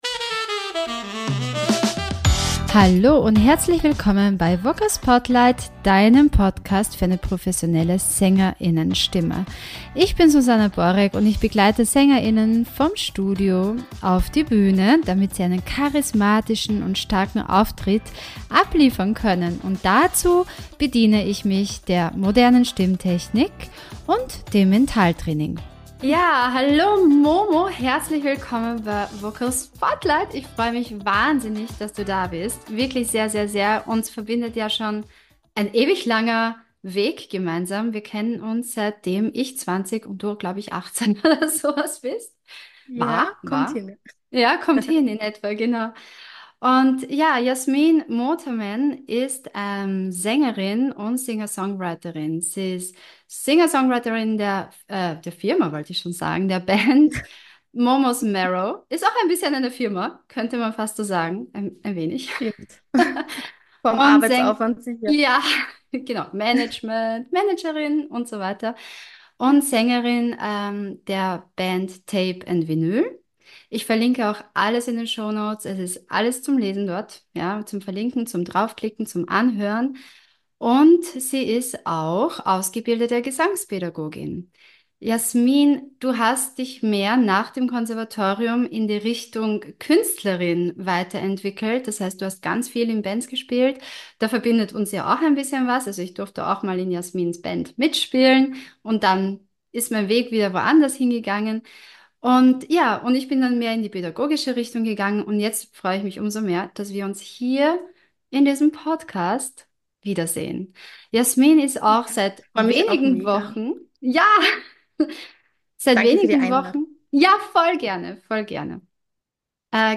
Interview mit Gesangsschülerin